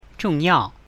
讀雙音節去聲詞時，我們可以想象成把一個四聲字分成兩個部分來讀，前一個四聲字是上半部分，後一個四聲字是下半部分，例如：重要 去聲詞語舉例： 愛好，愛護，按照，案件，暗示 報告，伴奏，暴動，變化，辨認，部位 側面，創造，次要，促進 大陸，帶動，但是，檔案，盜竊，電話，定義 個性，故意，貫徹，過度 奧運會，辦事處，對抗性，世界化
我們 在 讀連續兩個(或以上)去聲詞語時，其實調值並不是標準的51,51，因為說話時聲音不會中斷，兩個連續去聲的聲調後一個會比前一個更低。